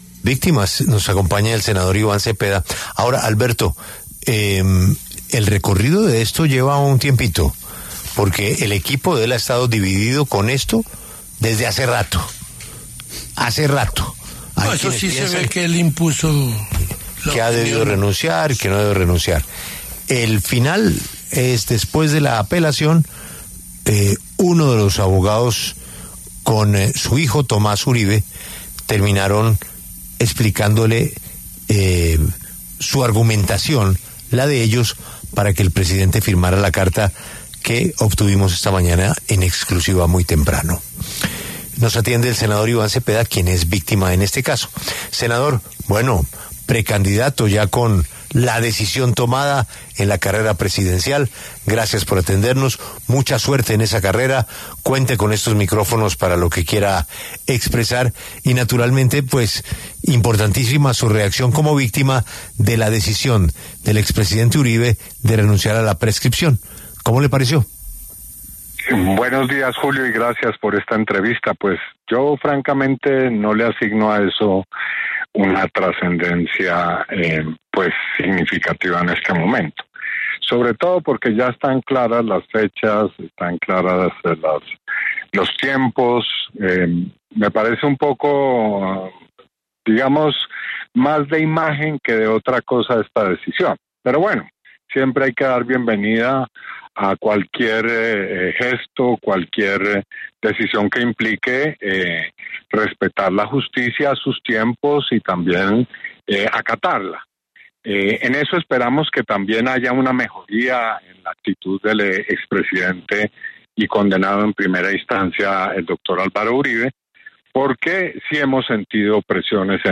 El senador y precandidato Iván Cepeda habla sobre la decisión del expresidente Álvaro Uribe de renunciar a la prescripción de su proceso por presunta manipulación de testigos.